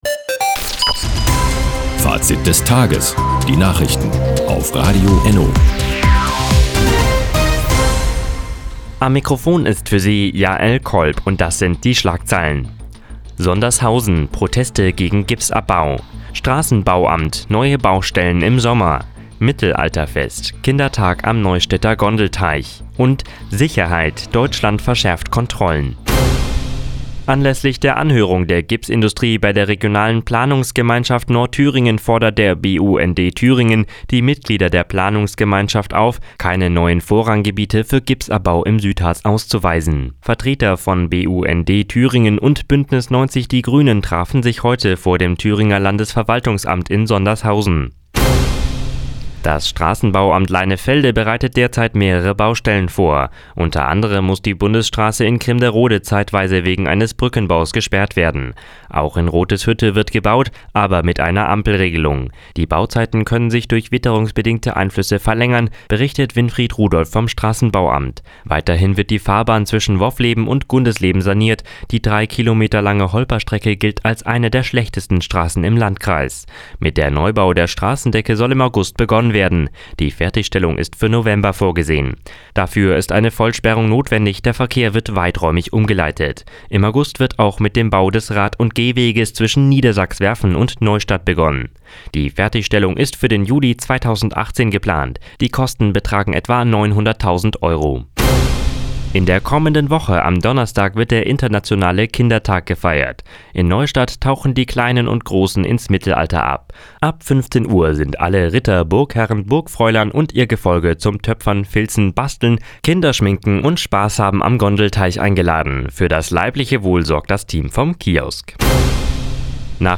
Die täglich Nachrichtensendung ist jetzt hier zu hören...
Nachrichten